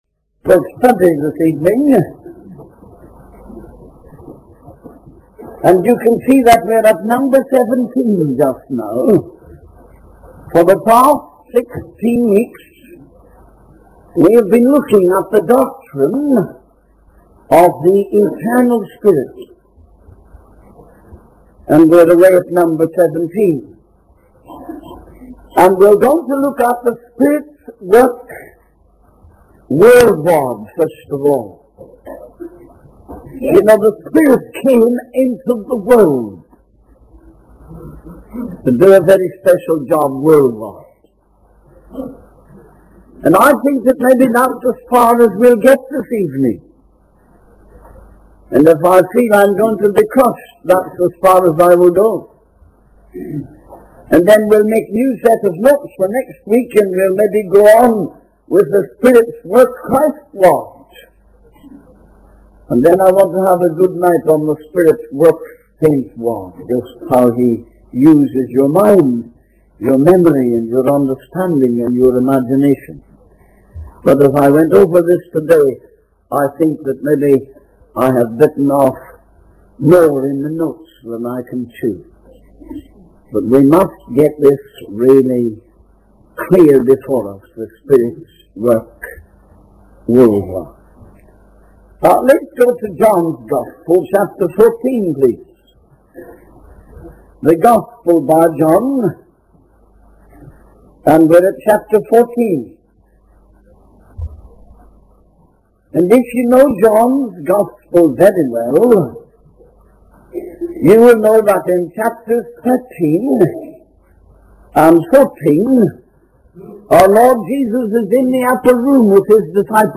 In this sermon, the preacher describes a scene where a man receives news of his wife's sudden death.